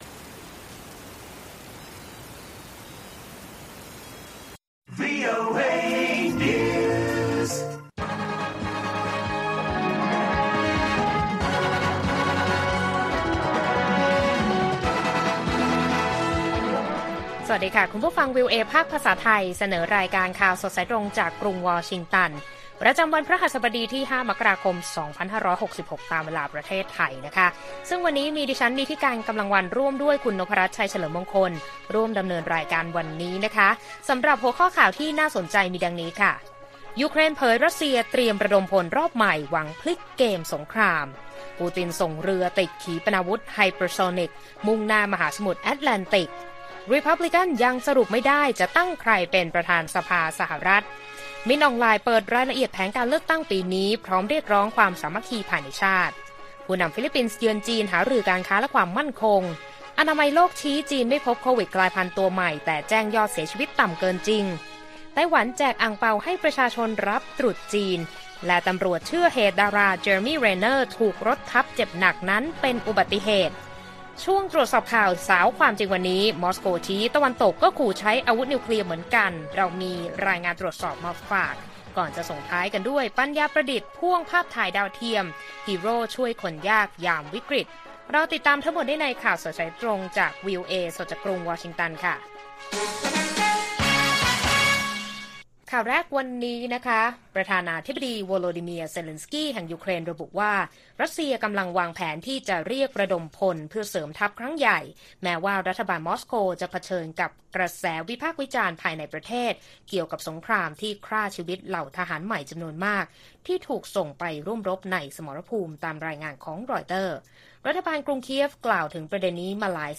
ข่าวสดสายตรงจากวีโอเอ ไทย พฤหัสฯ 5 มกราคม 2566